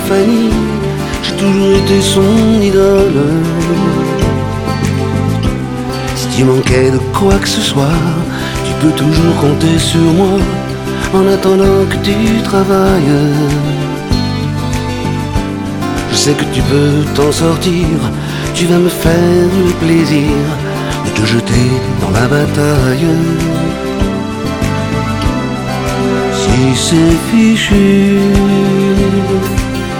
"frPreferredTerm" => "Chanson francophone"